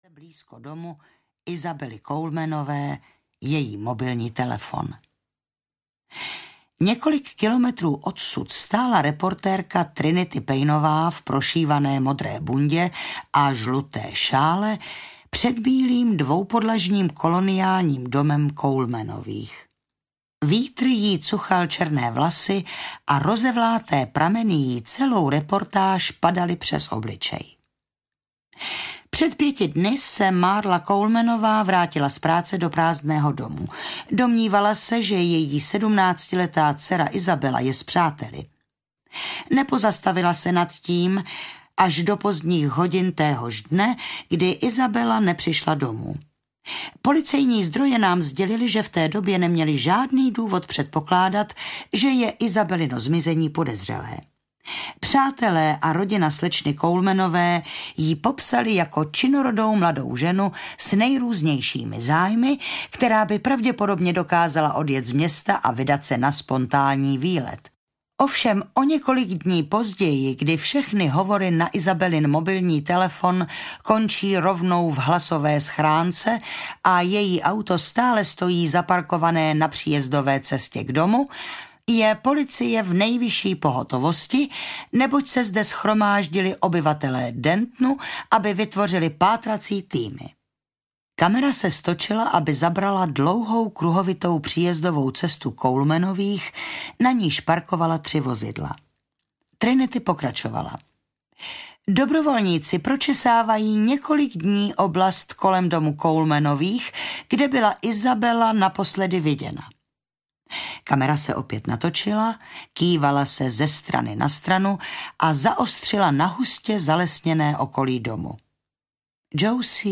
Mizející dívky audiokniha
Ukázka z knihy